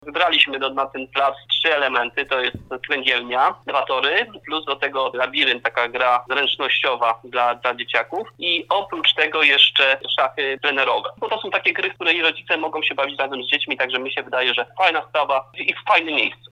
Inwestycje zrealizują wspólnie Lokalna Grupą Działania „Białe Ługi” i samorząd Staszowa mówi burmistrz Leszek Kopeć.